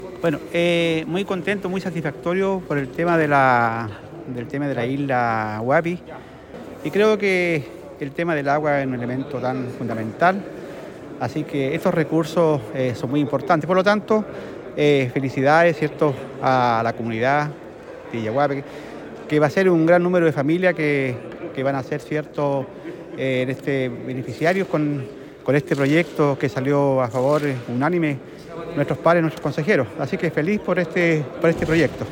Core_Carlos-Duhalde_Isla-Huapi.mp3